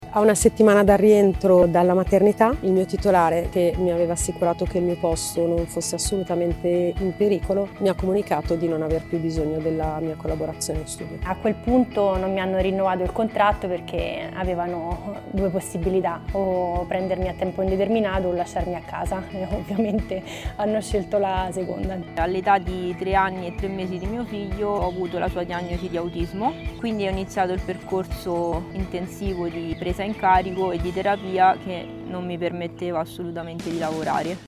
E’ lo studio di Save The Children sulla condizione della maternità in Italia, che approfondisce il divario di genere nel lavoro e nella cura familiare. Ascoltiamo alcune testimonianze raccolte dall’organizzazione.